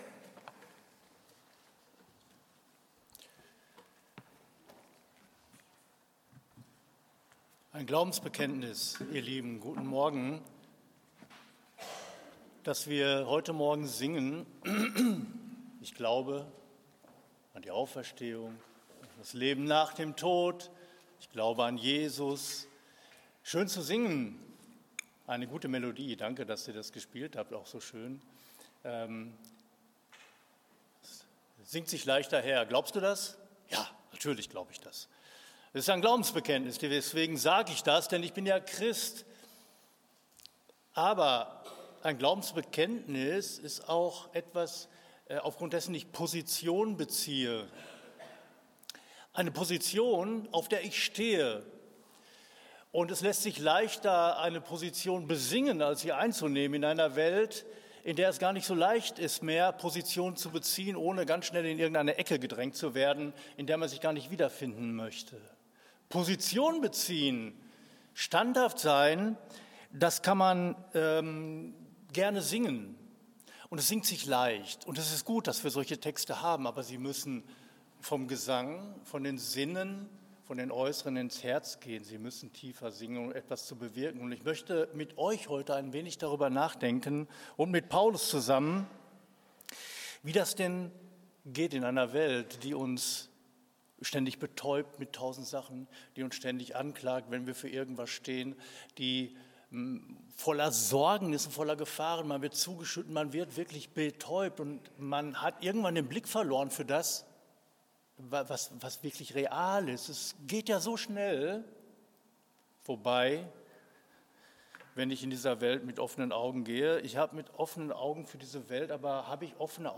Predigt 01.06.25
Predigt.mp3